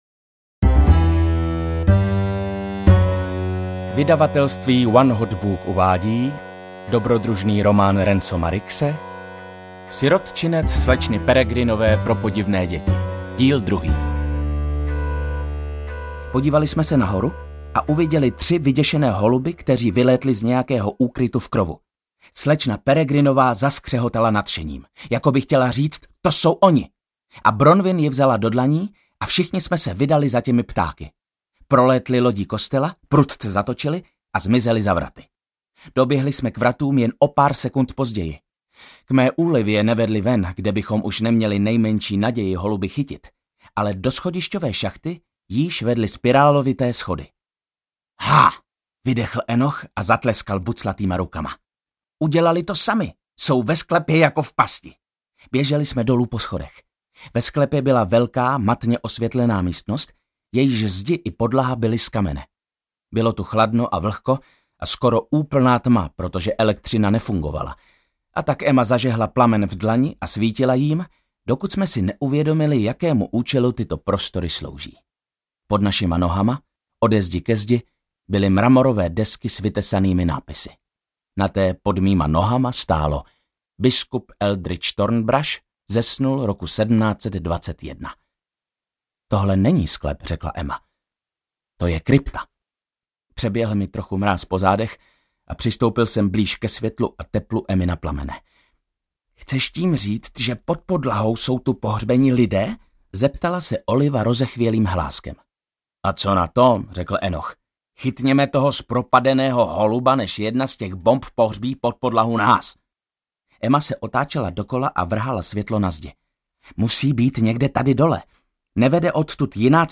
Ukázka z knihy
• InterpretViktor Dvořák